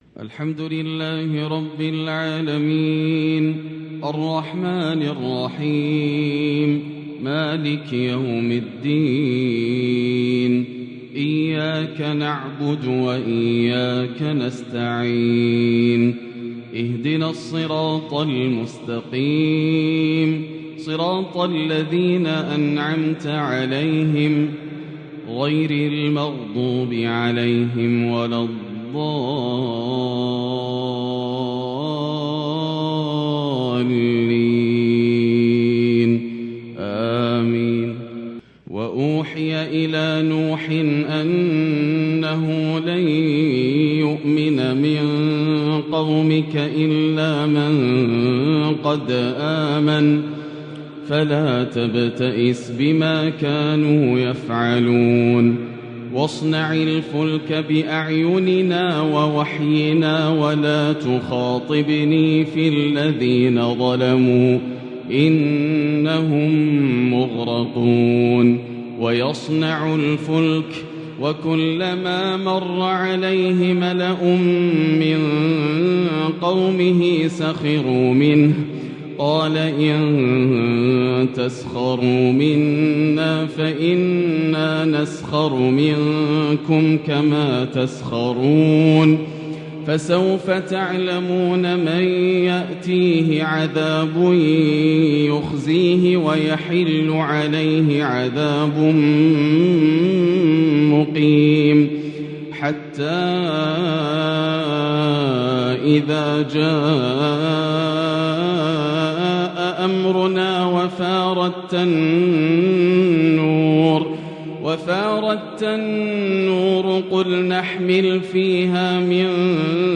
“ونادى نوح ابنه” تلاوات مذهلة تجلى في تحبيرها غريد الحرم الآسر د.ياسر الدوسري > تلاوات عام 1443هـ > مزامير الفرقان > المزيد - تلاوات الحرمين